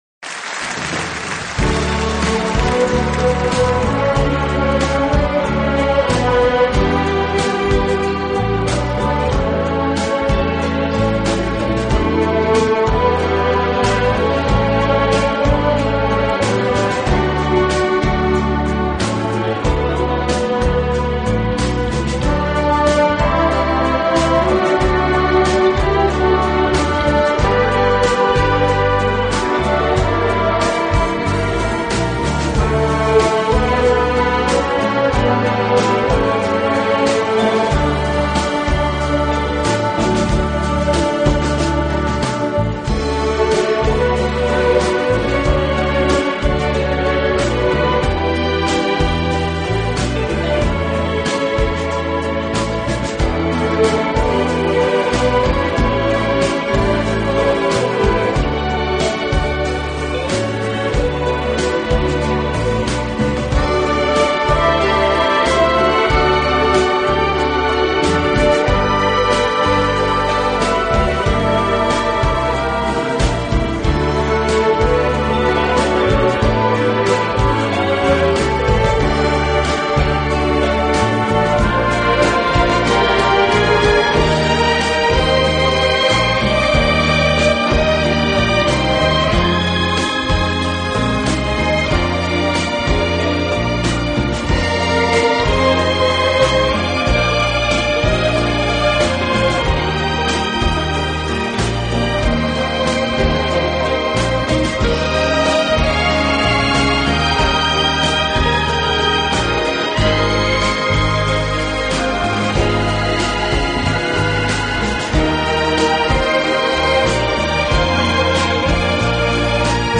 此外，这个乐队还配置了一支训练有素，和声优美的伴唱合唱队。